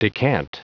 Prononciation du mot decant en anglais (fichier audio)
Prononciation du mot : decant